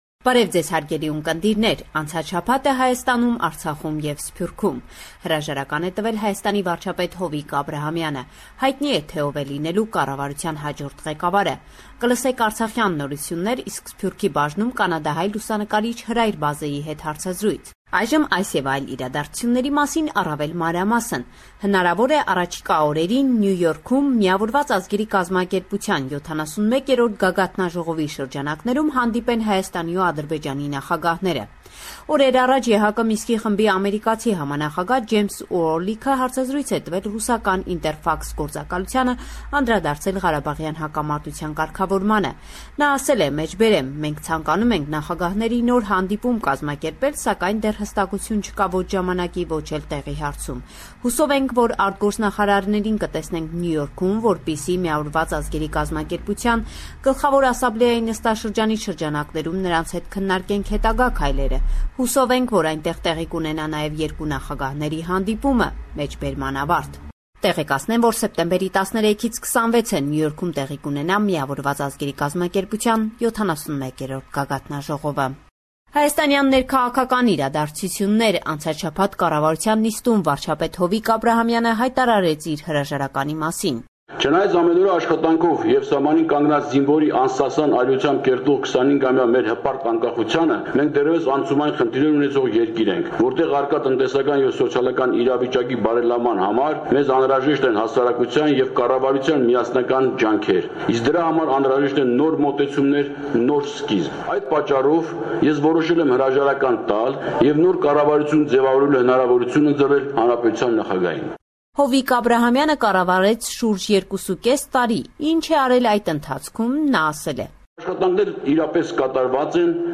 News from Armenia